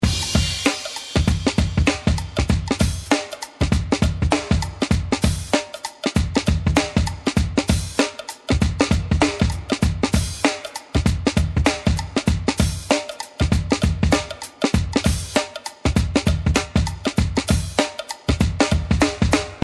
部落主环路
描述：用电子装置制作，配有原声镲片和HH
Tag: 96 bpm Fusion Loops Drum Loops 3.30 MB wav Key : Unknown